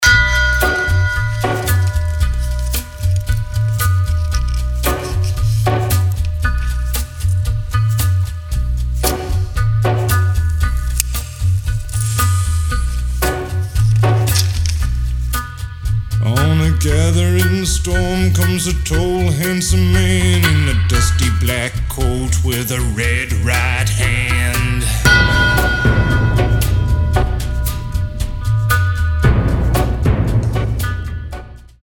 • Качество: 320, Stereo
атмосферные
спокойные
медленные
музыка в стиле блюз-рок, инди-рок